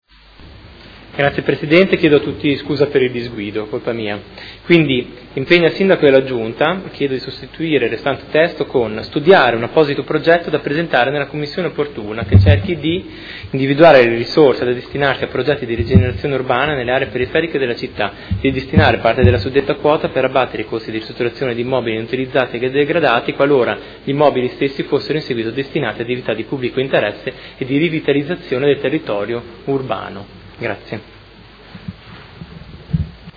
Seduta del 25 febbraio. Approvazione Bilancio: presentazione emendamento prot. 28017